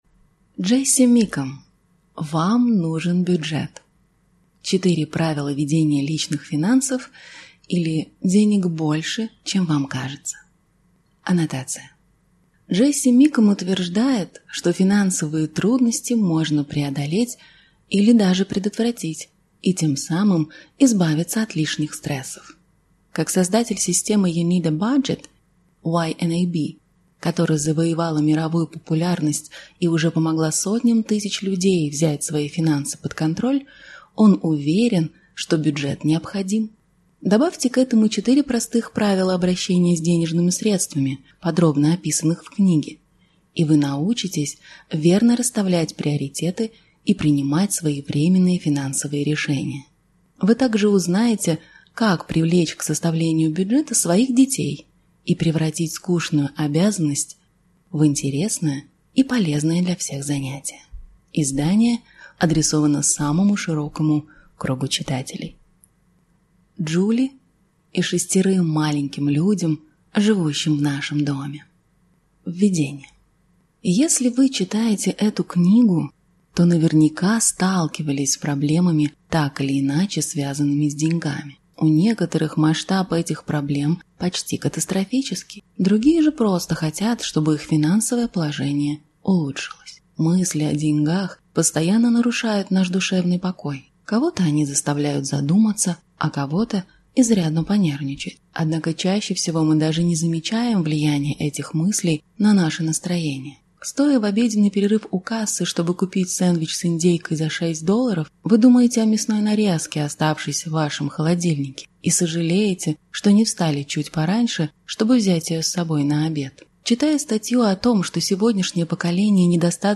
Аудиокнига Вам нужен бюджет. 4 правила ведения личных финансов, или Денег больше, чем вам кажется | Библиотека аудиокниг